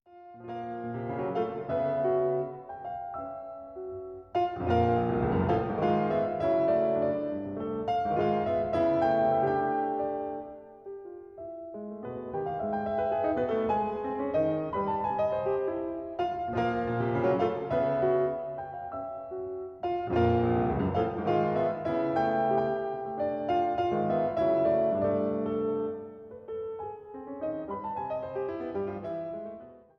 Lieder und Kammermusik
für Klavierquintett (1979): Con brio – meno mosso – con brio